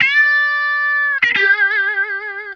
Index of /90_sSampleCDs/Zero-G - Total Drum Bass/Instruments - 2/track39 (Guitars)